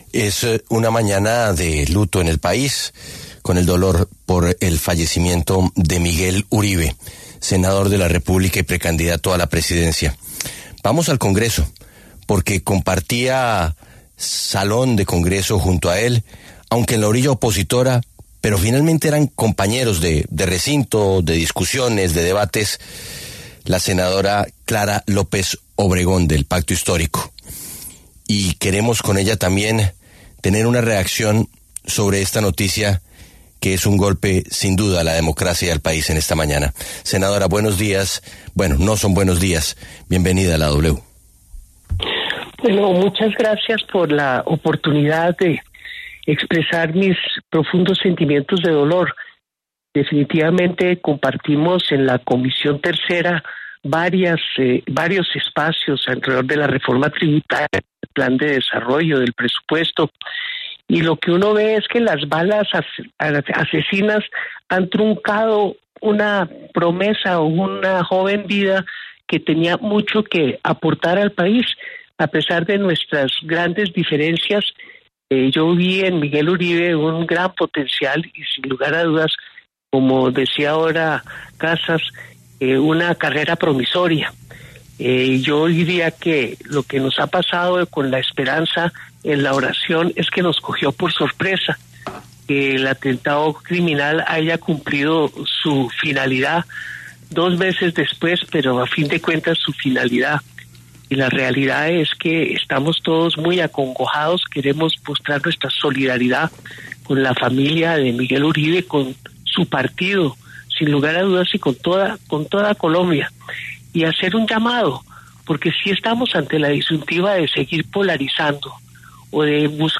La senadora del Pacto Histórico, Clara López Obregón, conversó con La W sobre el magnicidio contra Miguel Uribe Turbay.